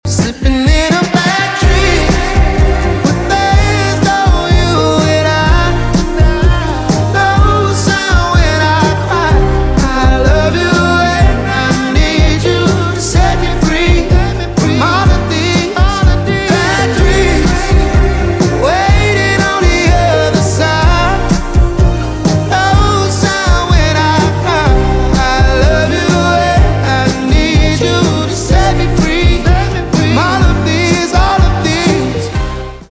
поп
барабаны , чувственные
романтические